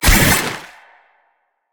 Sfx_creature_lillypaddler_flinch_swim_01.ogg